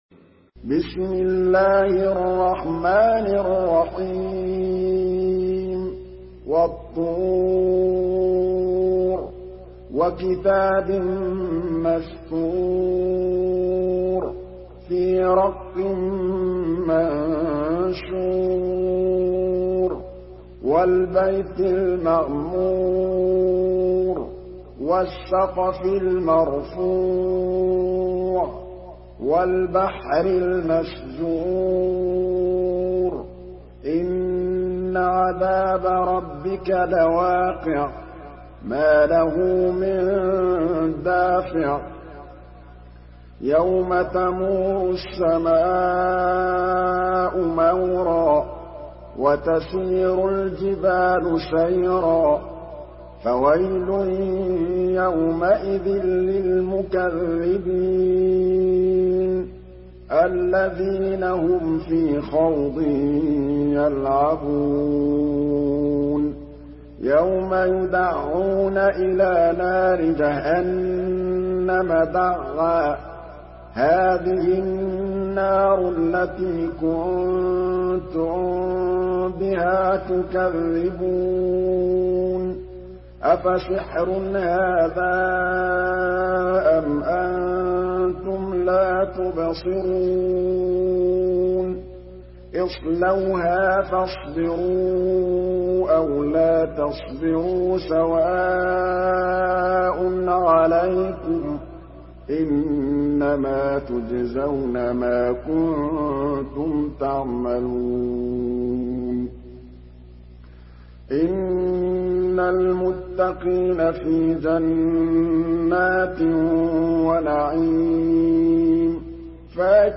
Surah আত-তূর MP3 by Muhammad Mahmood Al Tablawi in Hafs An Asim narration.
Murattal